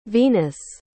Planeta Tradução em Inglês Pronúncia